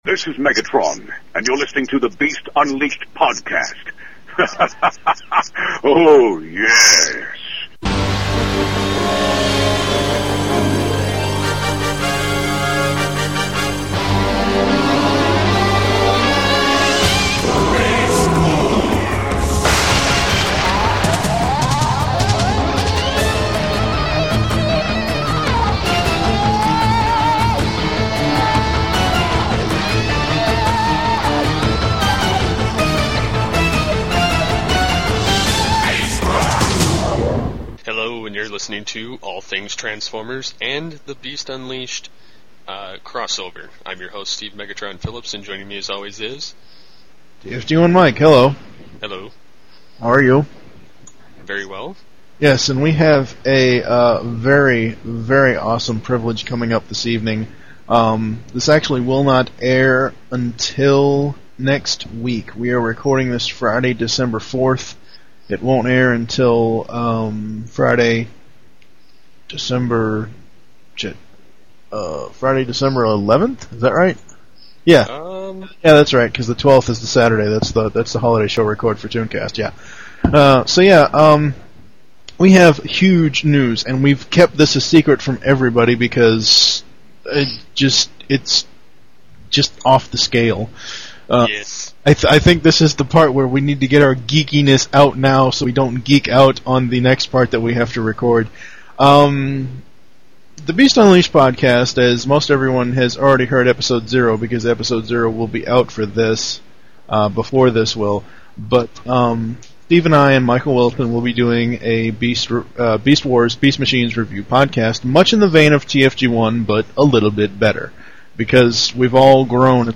Interviews – David Kaye